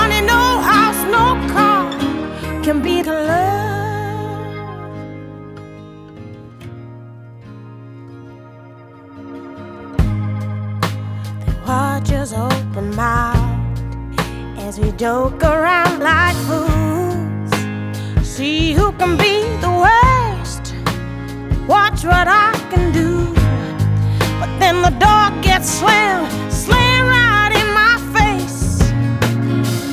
• Pop
mid-tempo soul and pop song
praising the gentle acoustics and the lyrics.